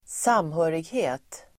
Uttal: [²s'am:hö:righe:t]